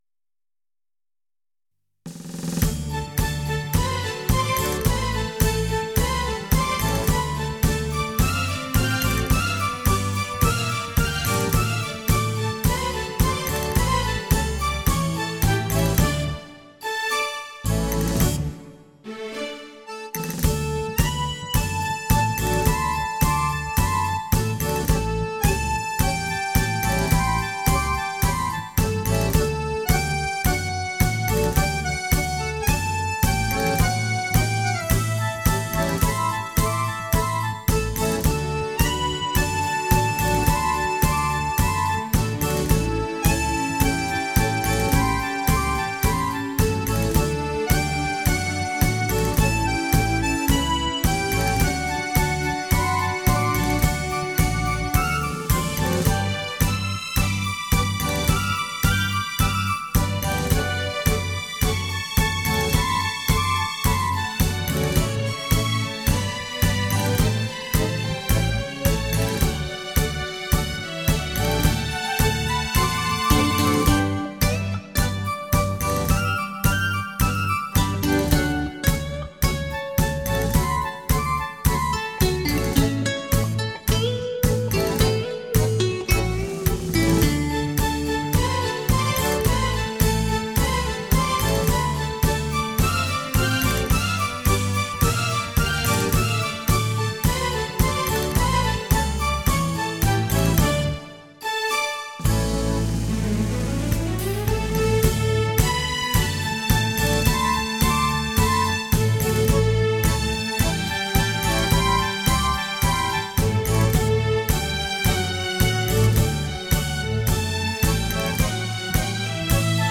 旋律优美动听